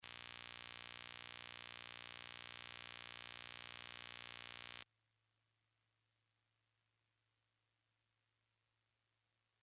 Clip 3 is similar to clip 2 except that in this example, we went into my Digitech GSP2101 and then right into a Maki 1604VLZ board. The guitars were held 18 inches away from the 21 inch monitor. As before, the same chord and volume settings were used for each guitar.
CLIP 3   Noise Eliminated!!!